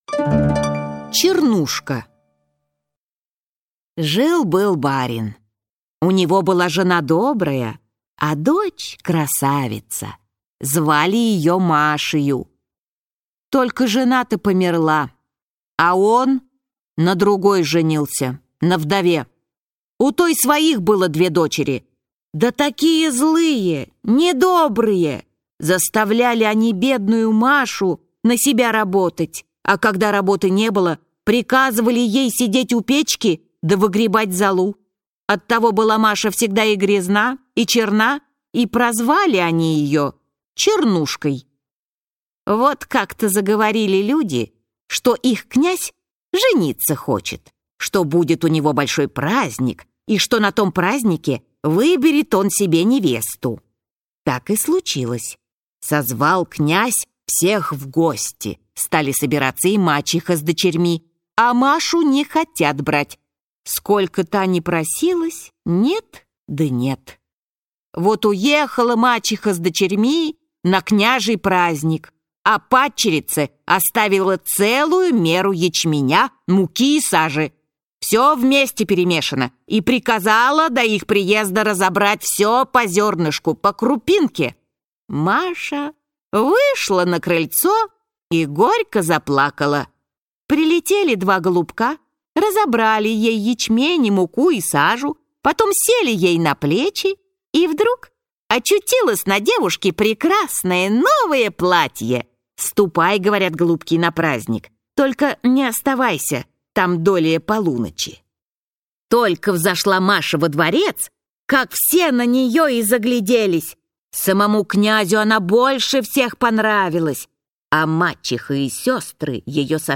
Аудиокнига Игралки. Считалочки. Дразнилки. Небылицы. Сказки | Библиотека аудиокниг